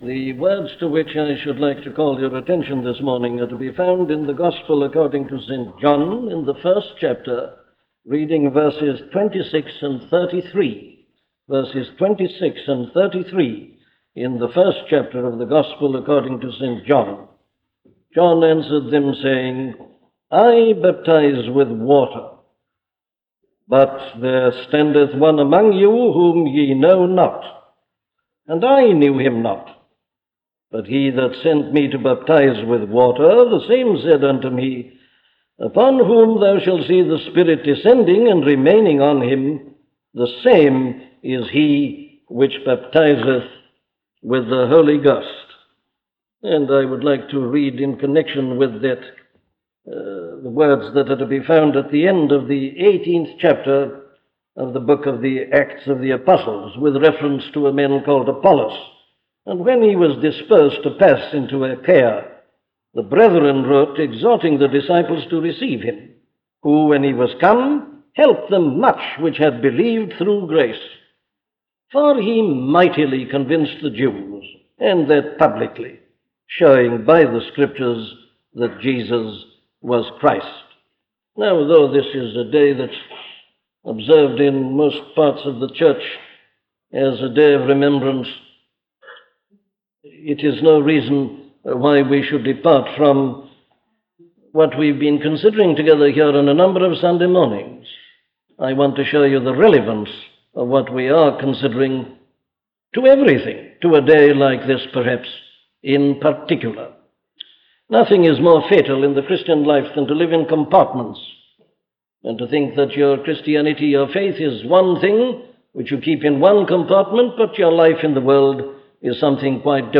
An audio library of the sermons of Dr. Martyn Lloyd-Jones.